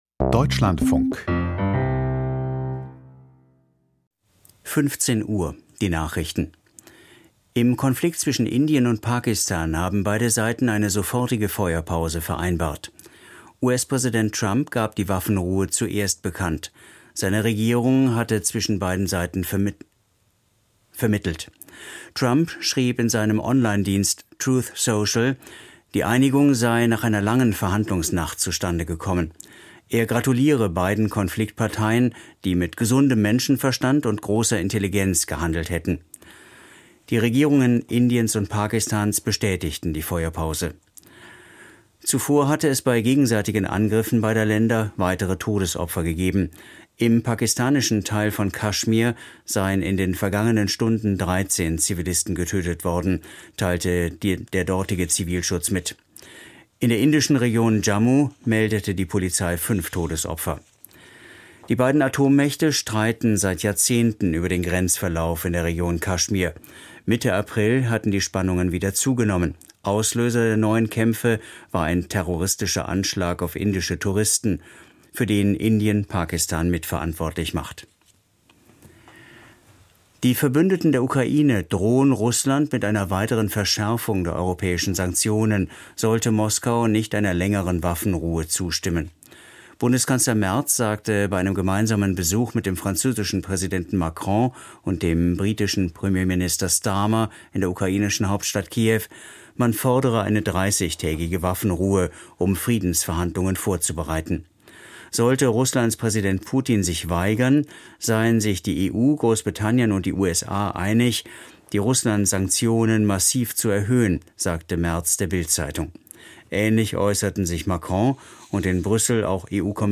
Die Nachrichten vom 10.05.2025, 15:00 Uhr
Aus der Deutschlandfunk-Nachrichtenredaktion.